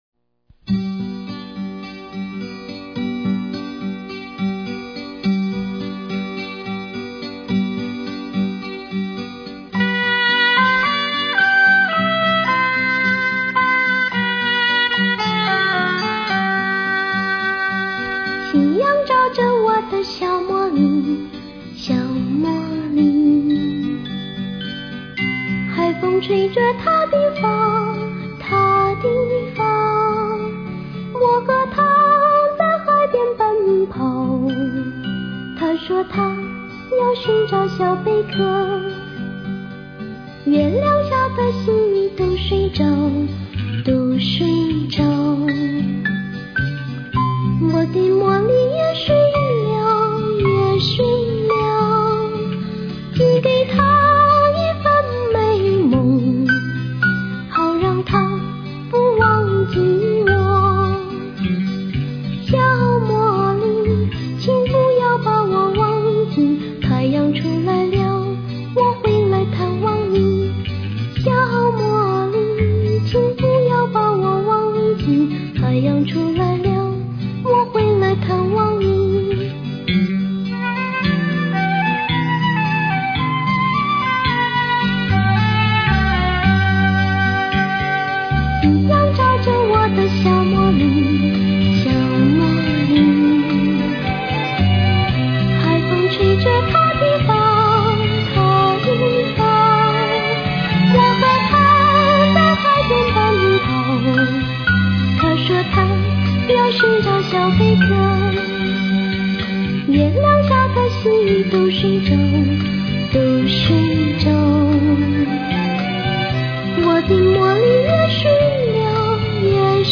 民谣